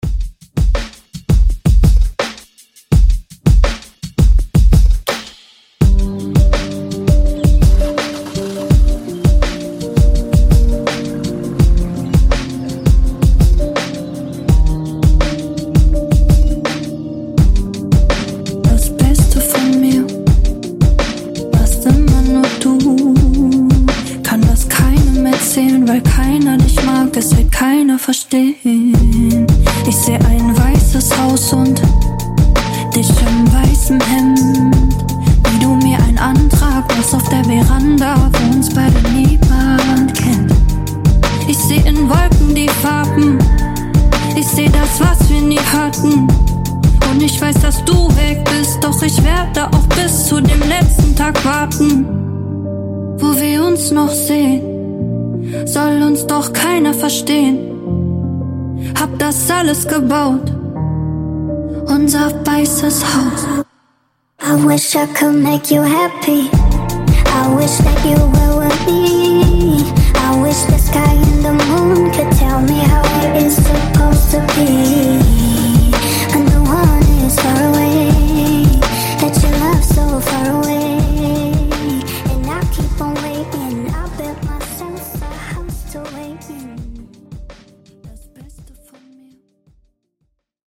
Genres: GERMAN MUSIC , RE-DRUM , TOP40
Clean BPM: 83 Time